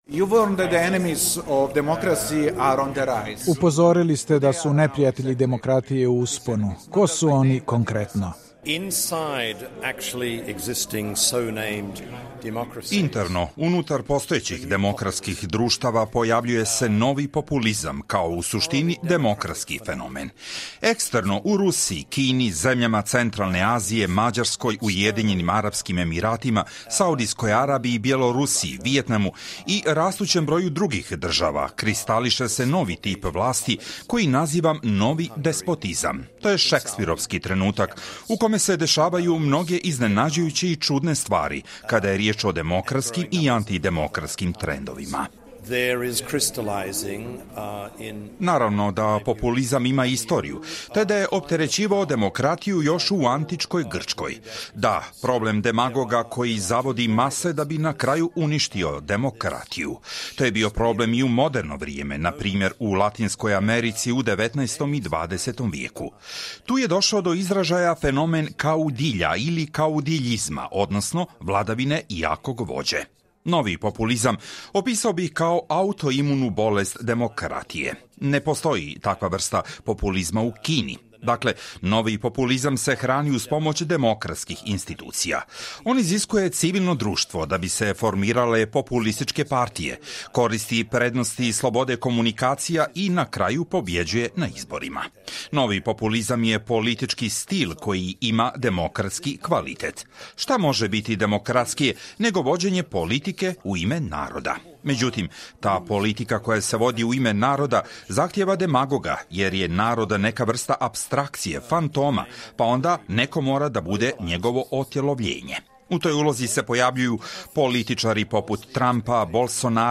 Intervju: Džon Kin